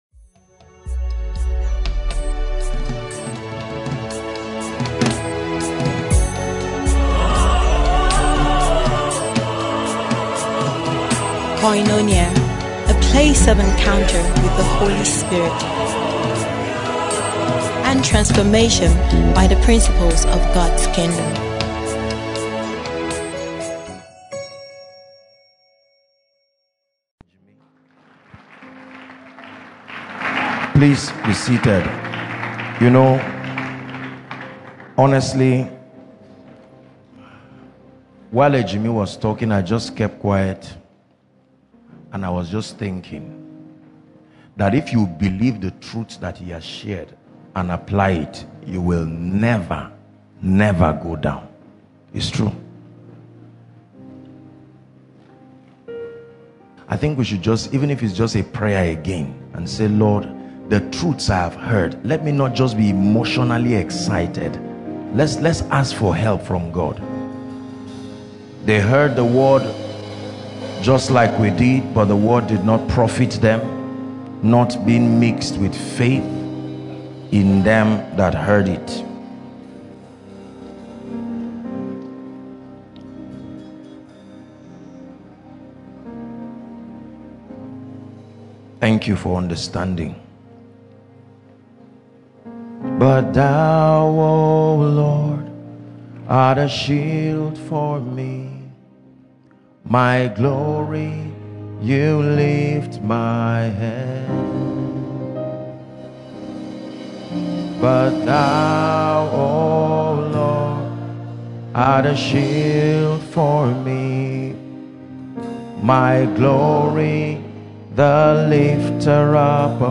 Business Seminar